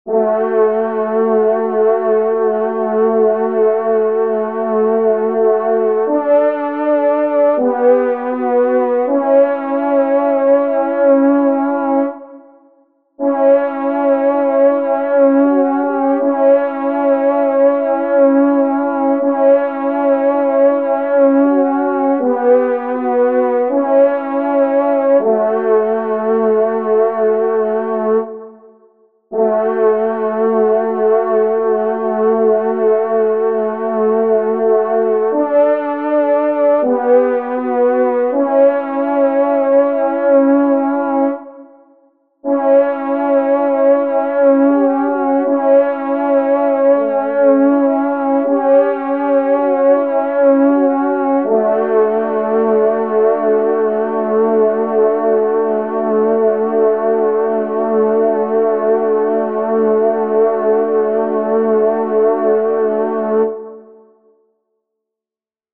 Genre : Fantaisie Liturgique pour quatre trompes
Pupitre 1° Trompe                     Pupitre 2°Trompe